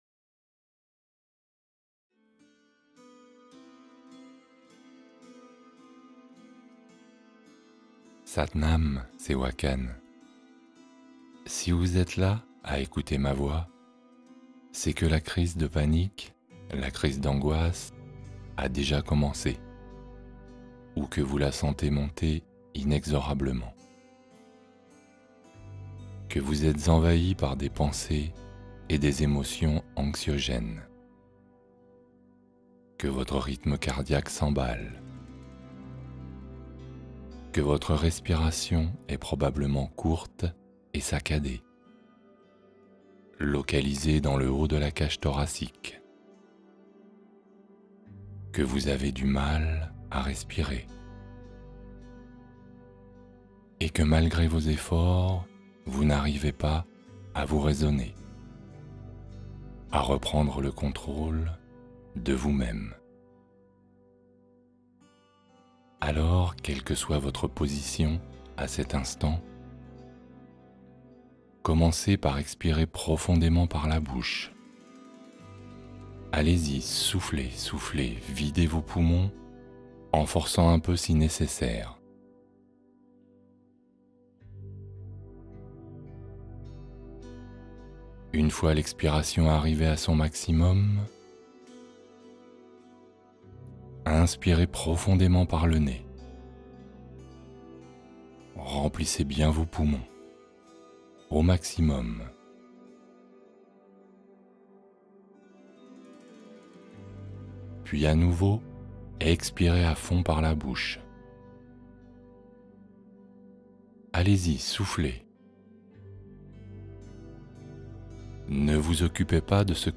Guérison sonore : immersion vibratoire avec le mantra Om